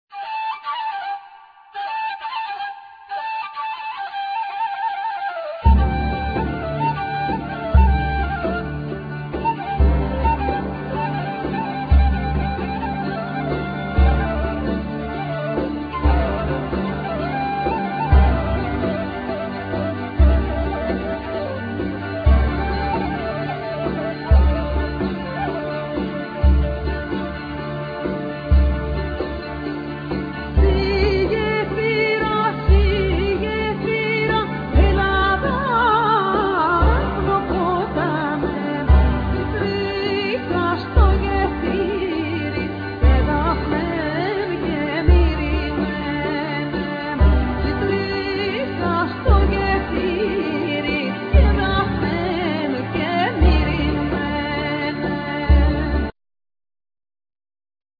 Loud,Mandola,Mandolin,Jura,Percussions
Vocals
Kaval,Gajda
Keyboards
Kemenche